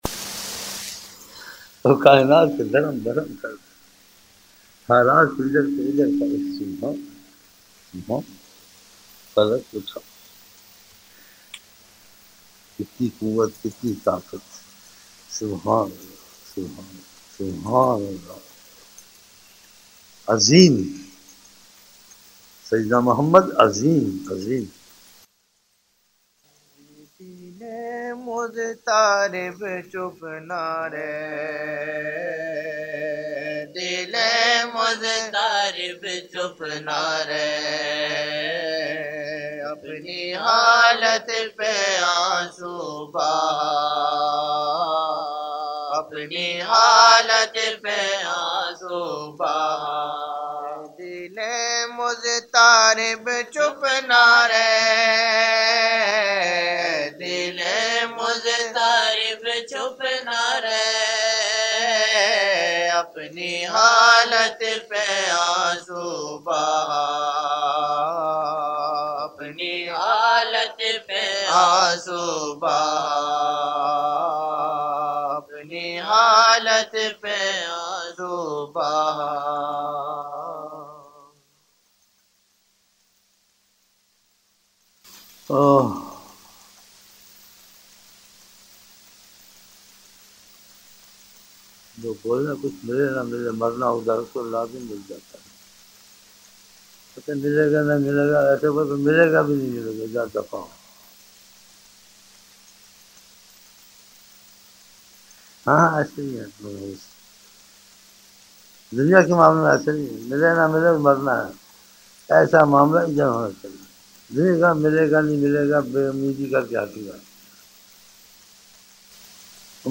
ظہر شروع کی محفل